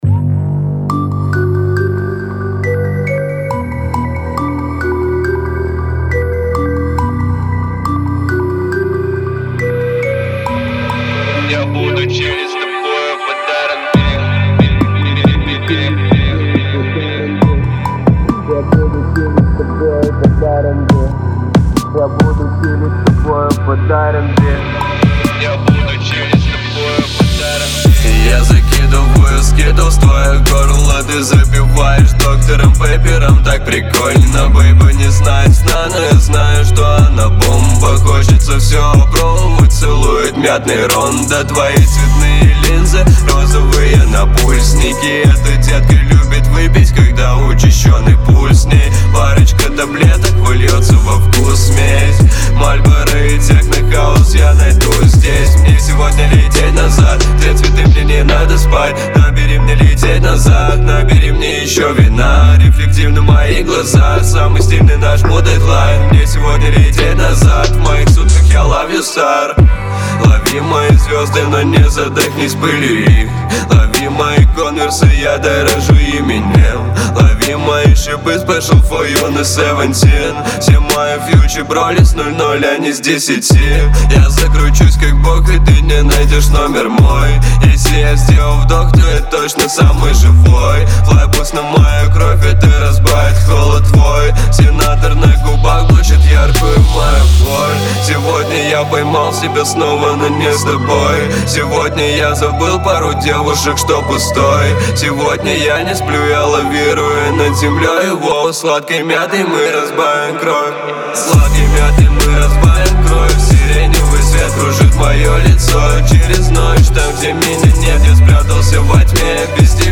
Как так обрабатывают вокал? Неужели каждую нотку тянут?